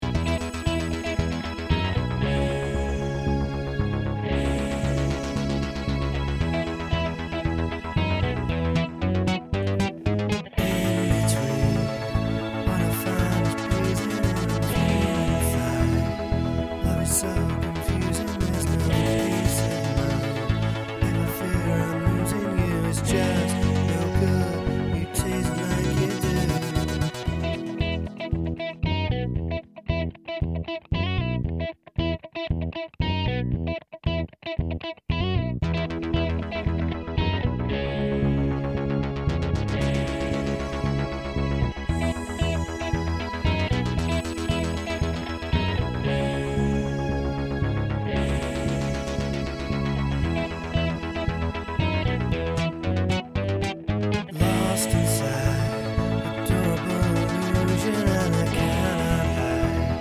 Minus Drums Pop (1980s) 3:36 Buy £1.50